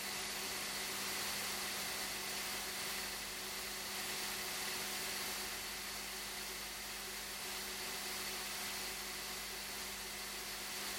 We have a significant spike at 50 Hz, most likely derived from the power grid’s frequency. The other notable frequency spikes are at 200 Hz, 400 Hz, and 2 KHz.
I have recorded the signals shown above, but please keep in mind that I’ve enabled Automatic Gain Control (AGC) to do so to make it easier for you to reproduce them.
10% Fan Speed